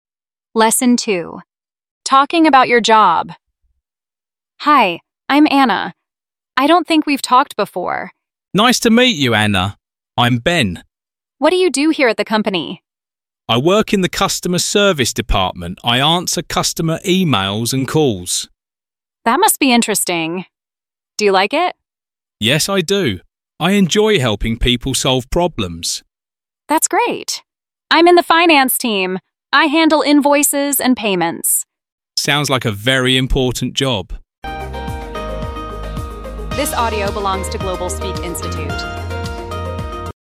Giọng tự nhiên